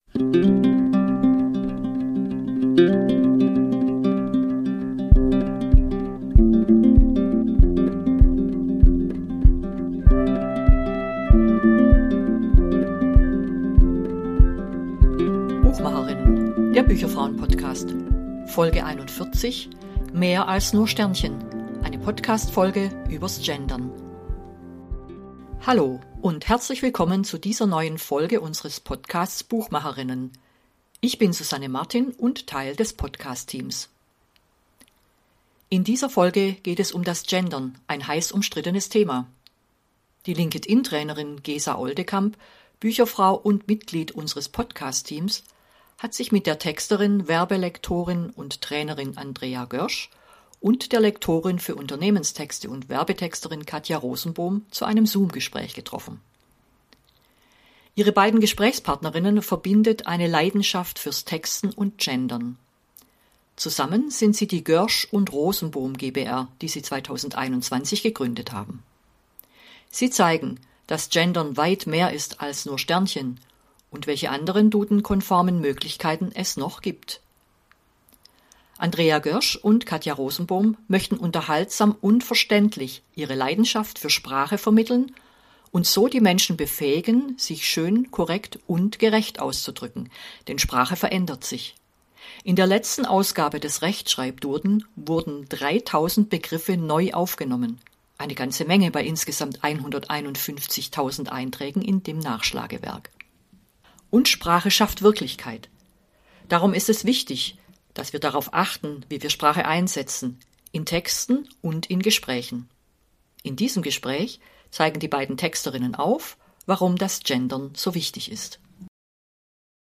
In dieser Folge geht es um das vieldiskutierte Sternchen. Die drei Gesprächspartnerinnen zeigen, dass Gendern weit mehr ist als nur Sternchen – und welche anderen dudenkonformen Möglichkeiten es noch gibt.